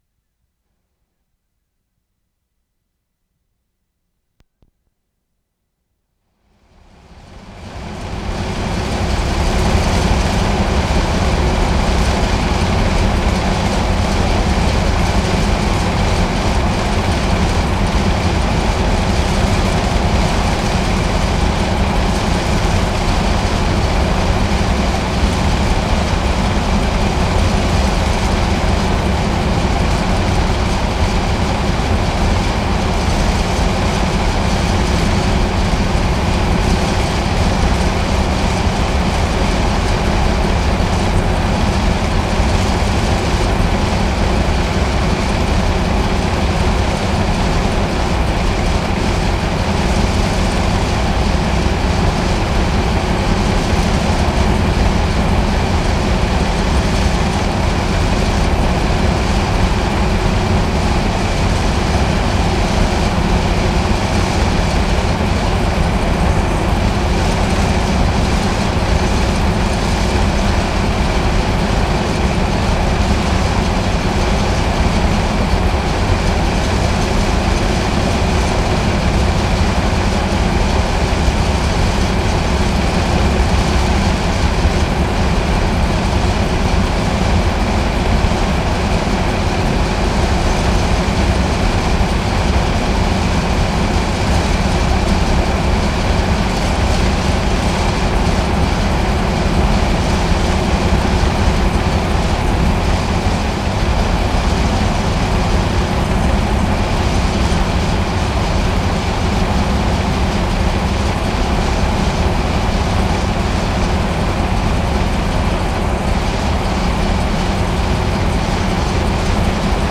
WORLD SOUNDSCAPE PROJECT TAPE LIBRARY
GAS SEPARATOR PLANT, diesel exhaust 2'15"
4. Four diesel engines pumping salt water back into ground. Rhythmically complex patterns.
5. Same as take 4, from different position. Not as clear as above take, more of a general hum and noise.